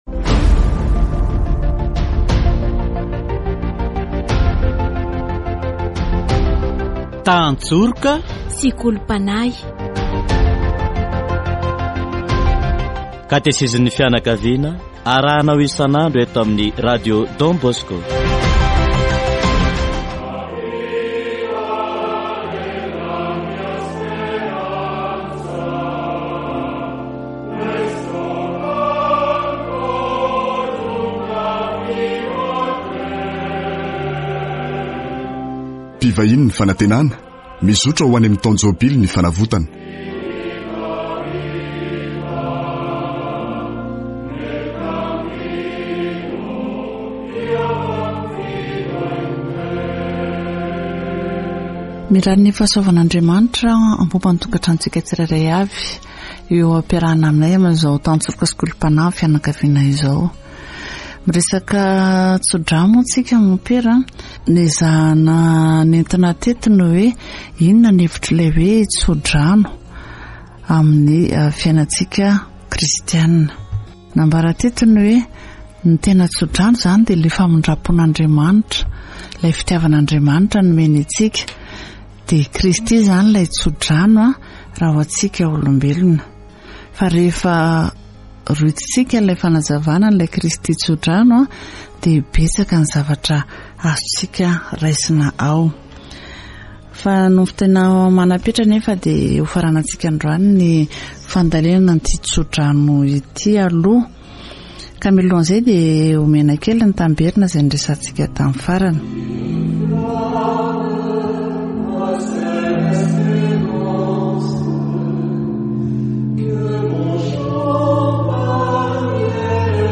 Catégorie : Approfondissement de la foi
Catéchèse sur la bénédiction